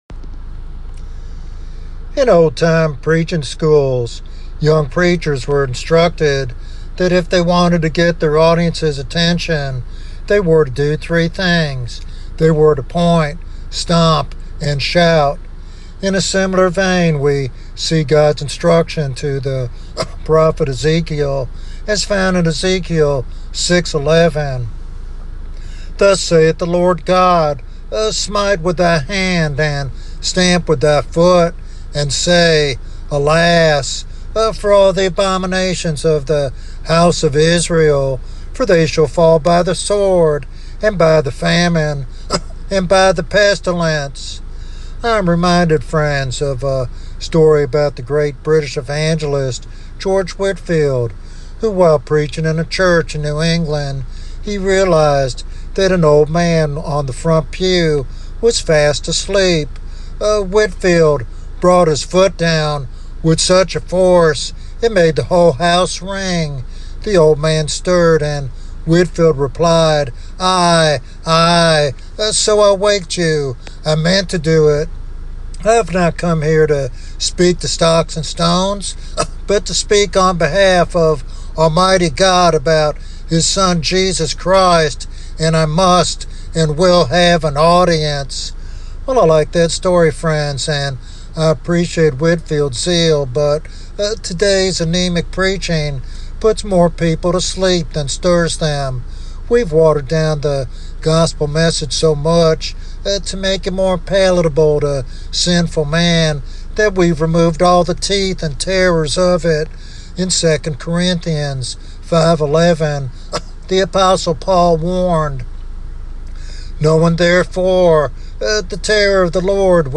Sermon Outline